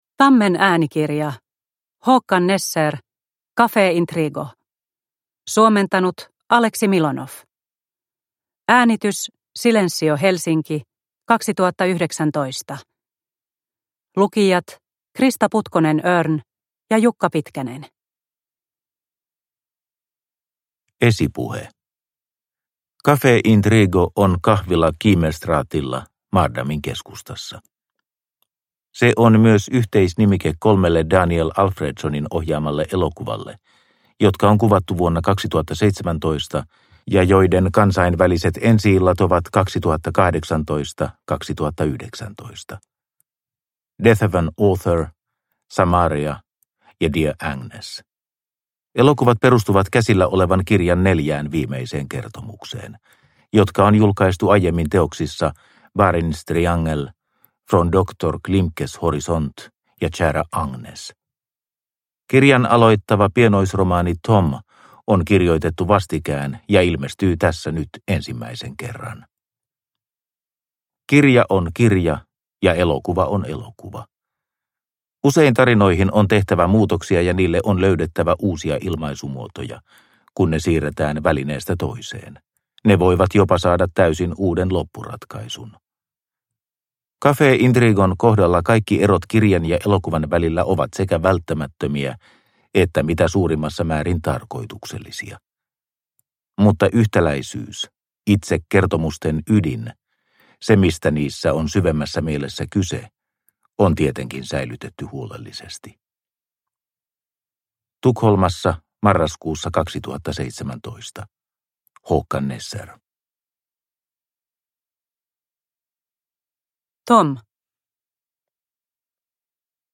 Café Intrigo – Ljudbok – Laddas ner